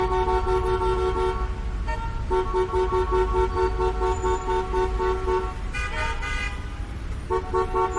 Warp Drive is a free sci-fi sound effect available for download in MP3 format.
324_warp_drive.mp3